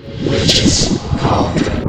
get_cauldron.ogg